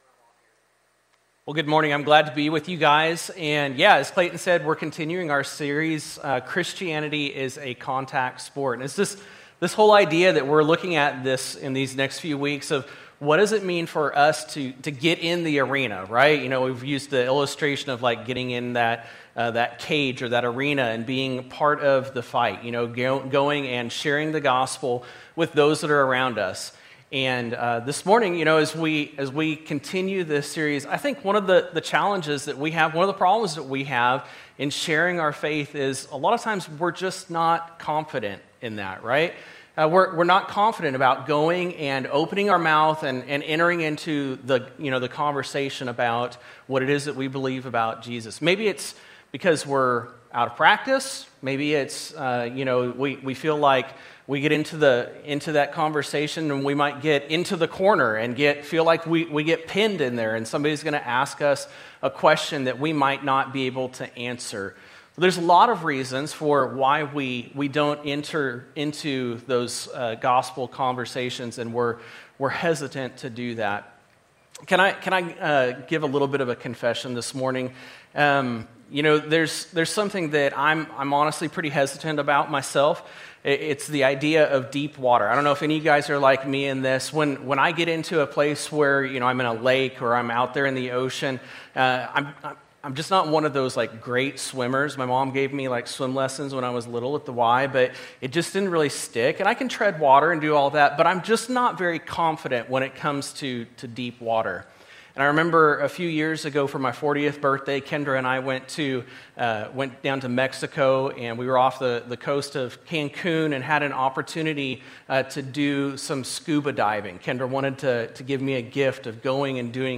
A message from the series "Ordinary Heroes."